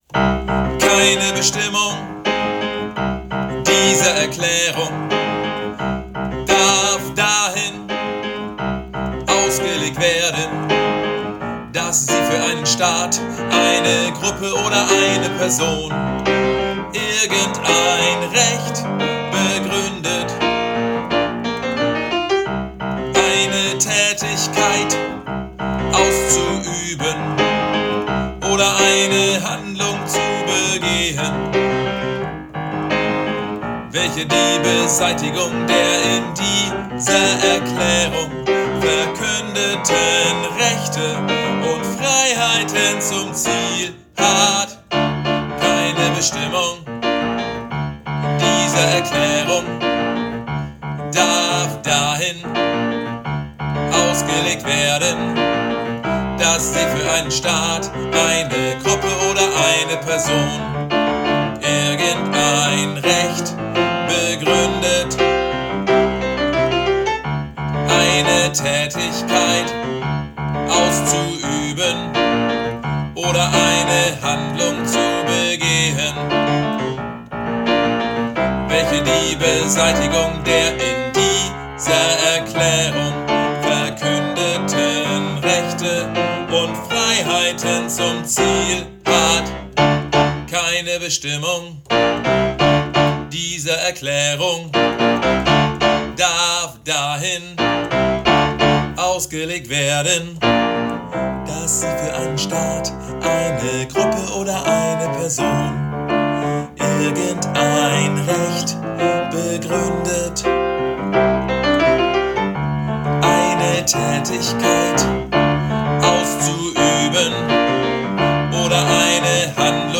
Übe-Dateien
MEZZO